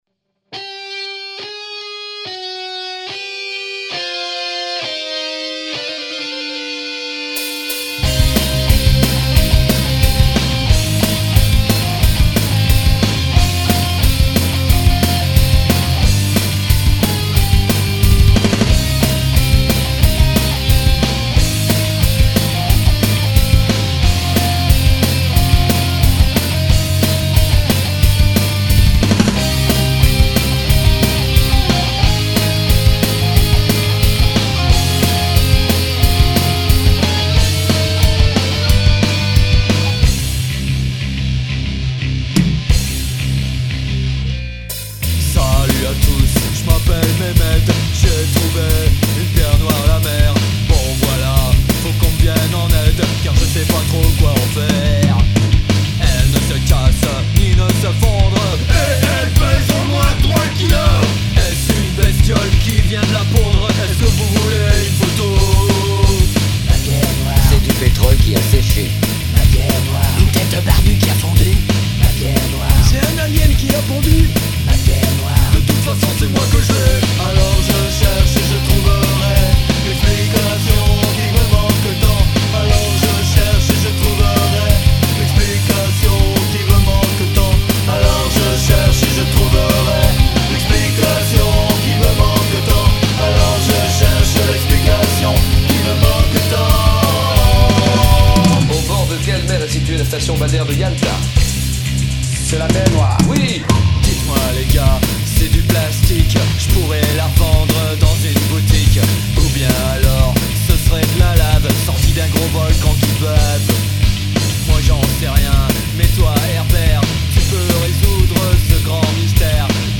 Nouvelle version, tjrs du meme pote:
les fills bien placés, un peu de double pour foutre la patate  [:neuf]